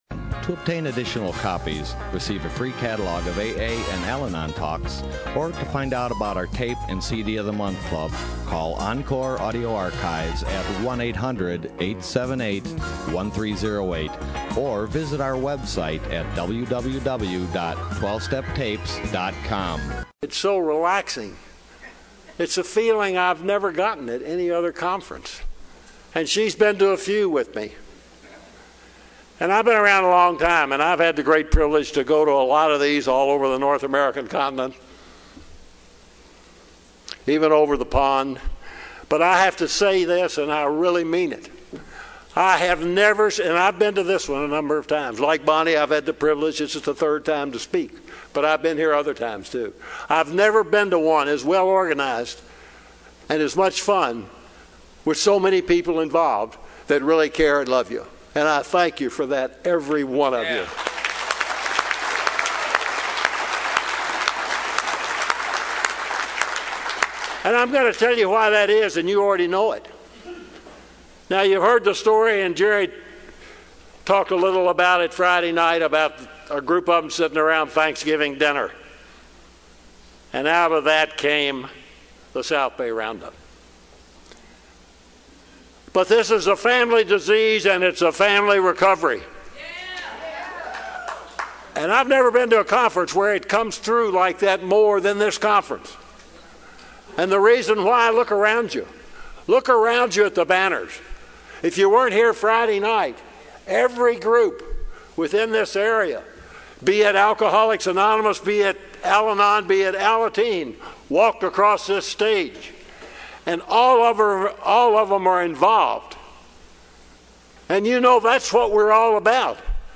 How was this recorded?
SOUTHBAY ROUNDUP 2014